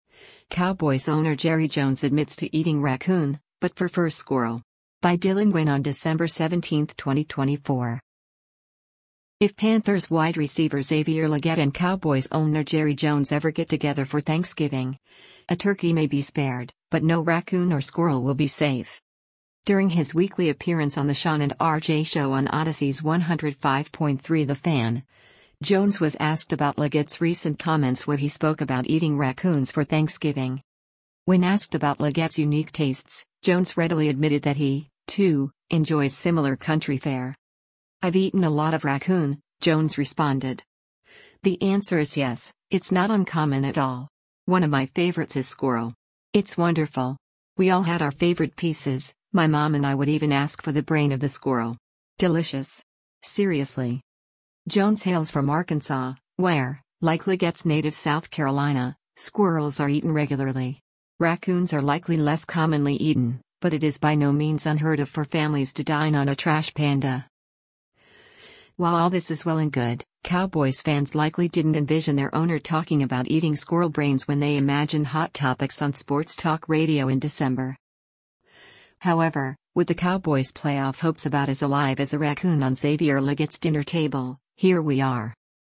During his weekly appearance on the Shan & RJ Show on Audacy’s 105.3 The Fan, jones was asked about Legette’s recent comments where he spoke about eating raccoons for Thanksgiving.